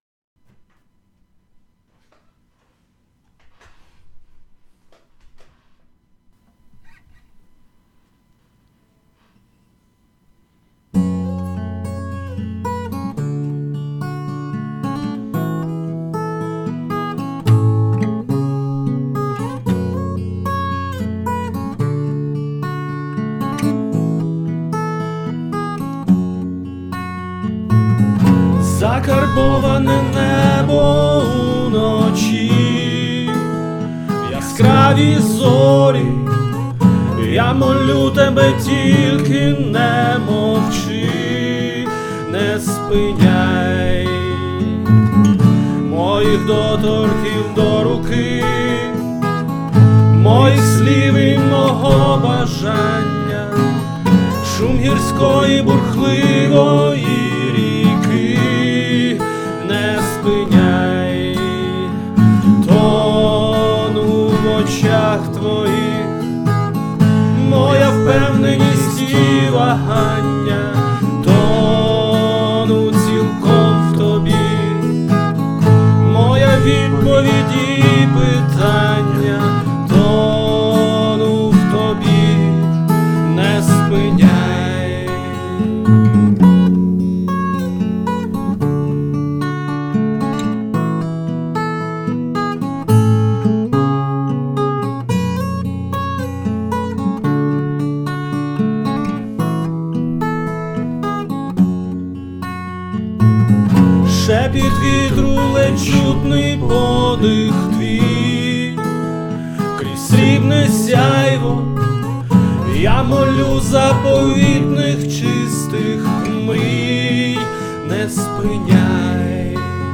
ТИП: Пісня
СТИЛЬОВІ ЖАНРИ: Ліричний
12 12 12 Ніжний романс зачаровує!